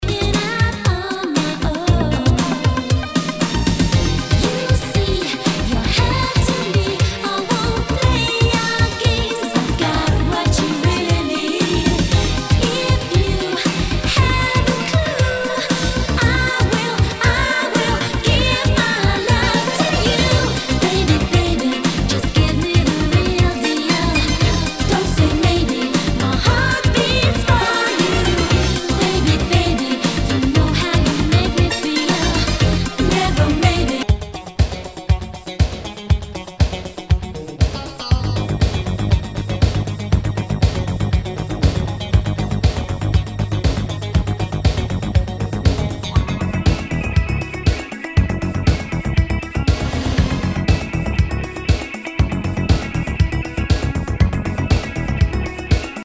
HOUSE/TECHNO/ELECTRO
ナイス！シンセ・ポップ / ヴォーカル・ハウス・クラシック！